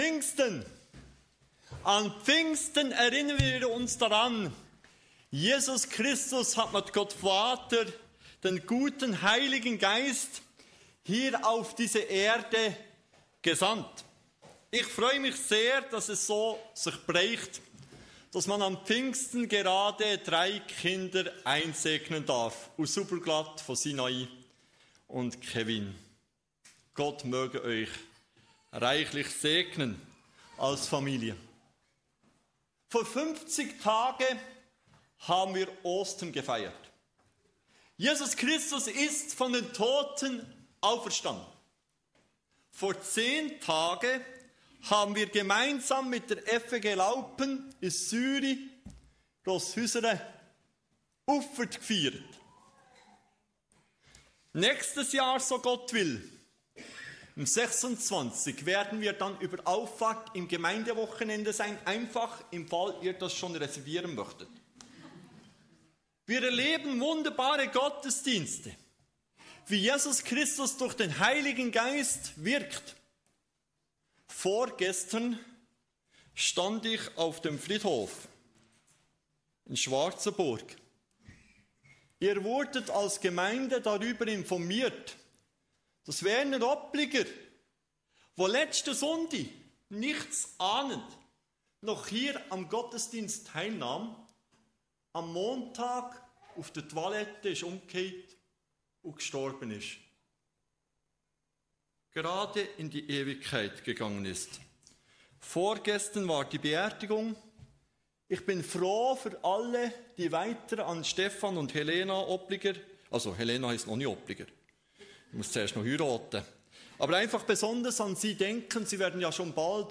Kategorie: Gottesdienst